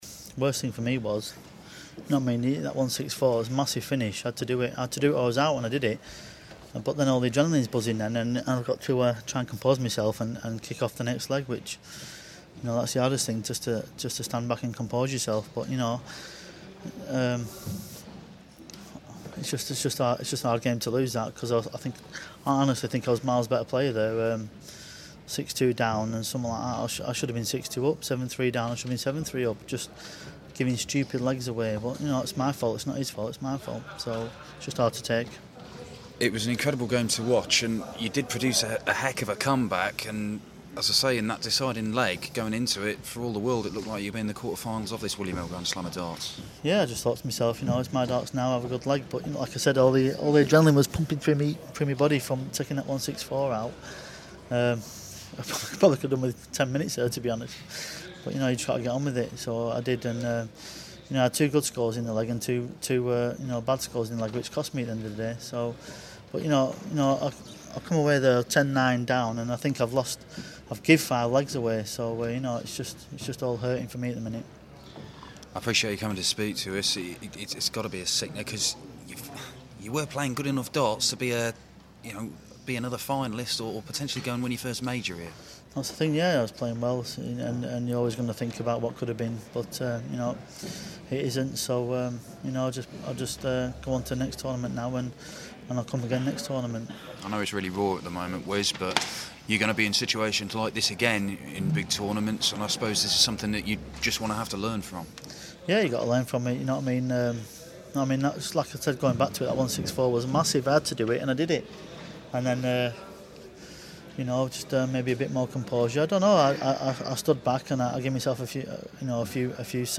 William Hill GSOD - Newton Interview (Last 16)